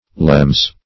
Meaning of lemures. lemures synonyms, pronunciation, spelling and more from Free Dictionary.